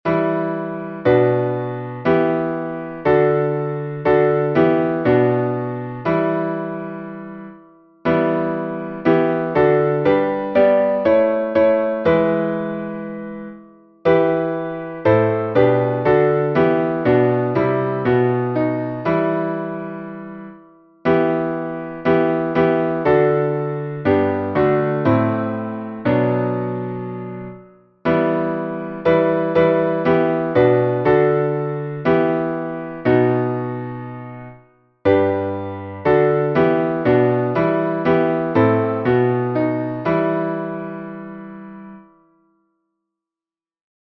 Modo: dórico
salmo_95A_instrumental.mp3